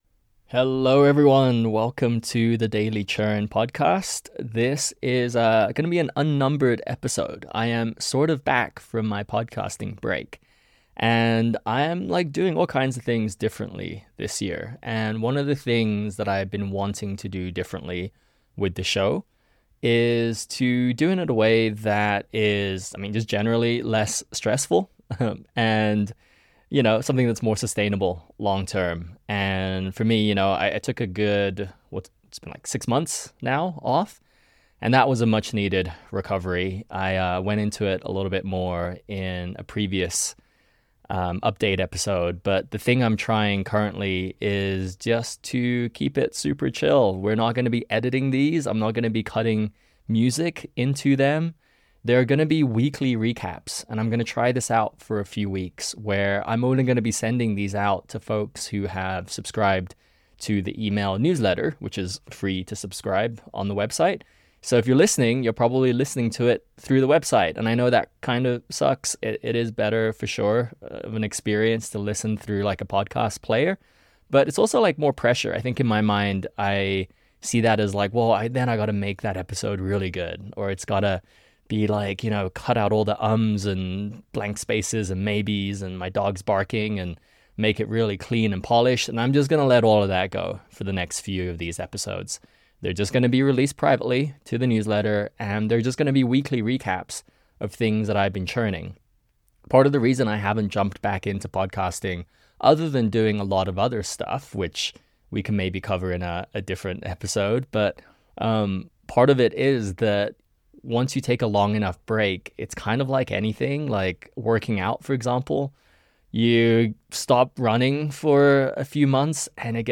These have minimal planning and zero editing, which means I can (hopefully) record a new one every week.